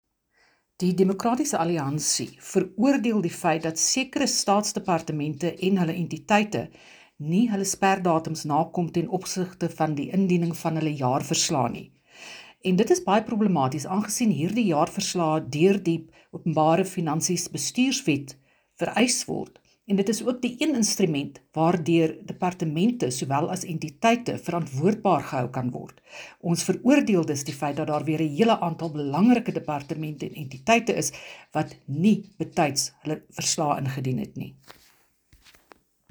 Afrikaans soundbites by Dr Annelie Lotriet MP